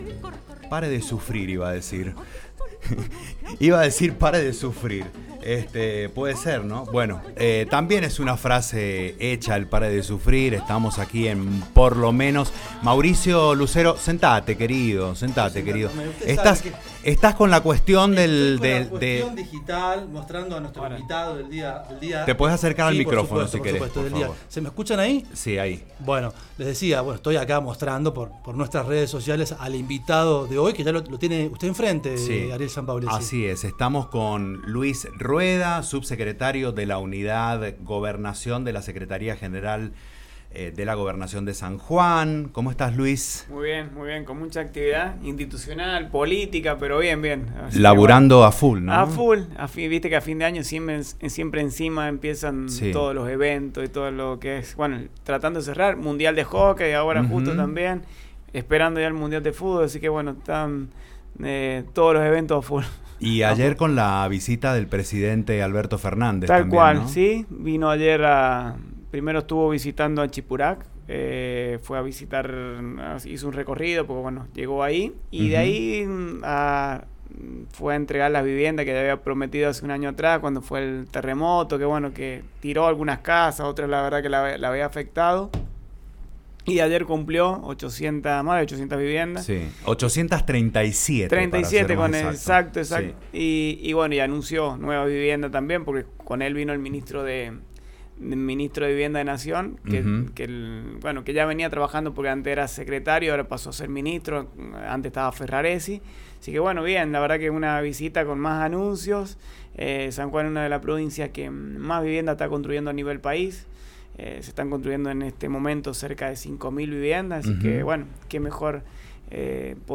En una entrevista de Condado Radio San Juan, Luis Ruedas, subsecretario de la Unidad de Gobernación de San Juan y presidente del Partido Bloquista habló sobre la situación política del país y de la provincia.